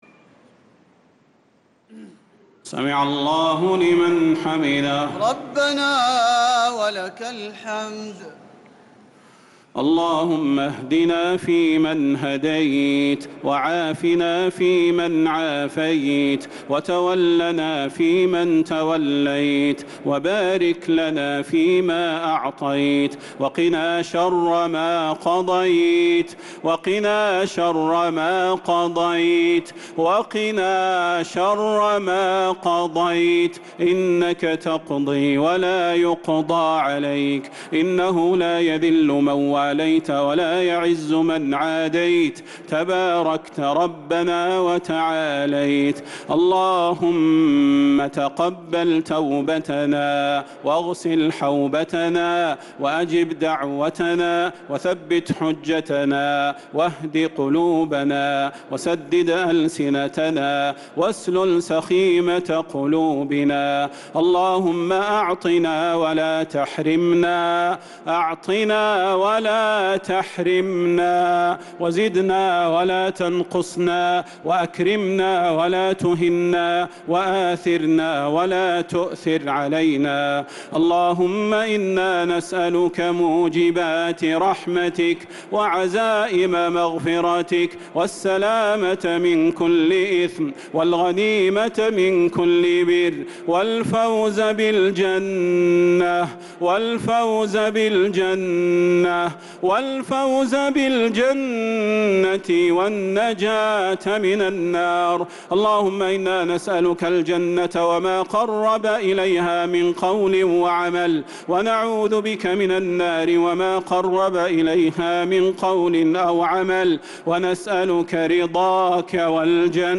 دعاء القنوت ليلة 25 رمضان 1446هـ | Dua 25th night Ramadan 1446H > تراويح الحرم النبوي عام 1446 🕌 > التراويح - تلاوات الحرمين